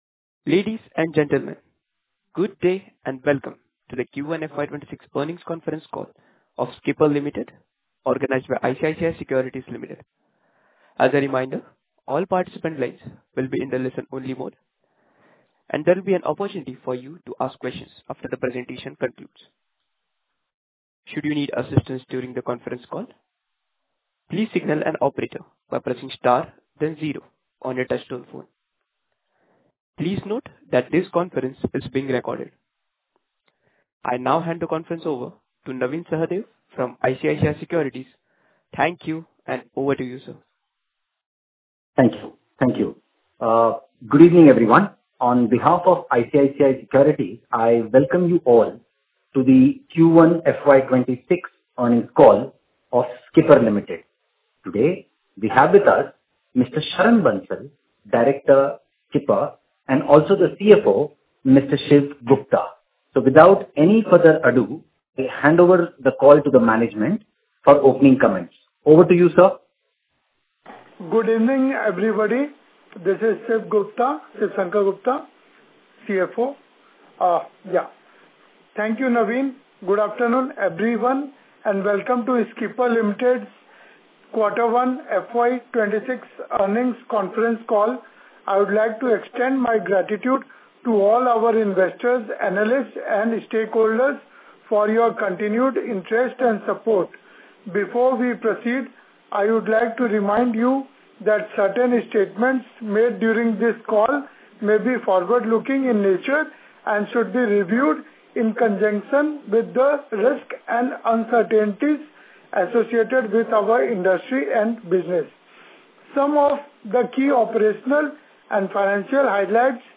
Earnings Con-call